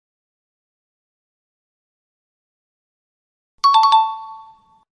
Alarm Clock
Alarm Clock is a free sfx sound effect available for download in MP3 format.
551_alarm_clock.mp3